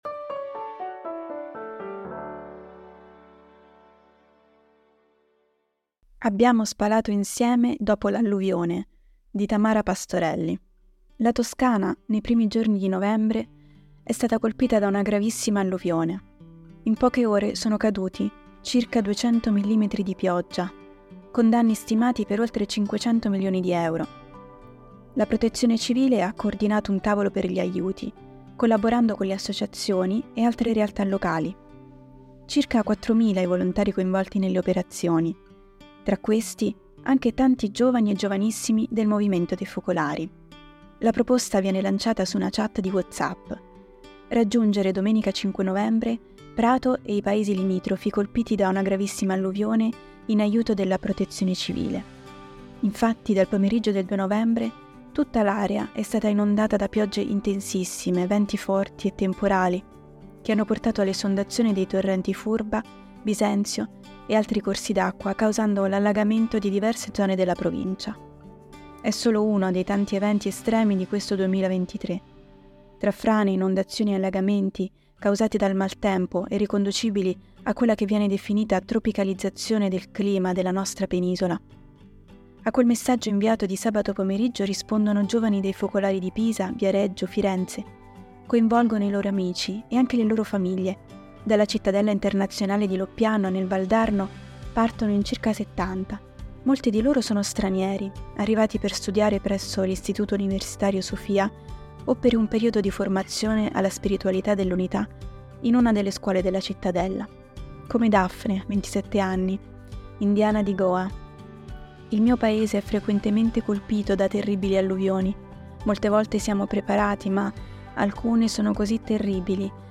Per ogni numero, ci sarà una selezione di articoli letti dai nostri autori e collaboratori.
Al microfono, i nostri redattori e i nostri collaboratori.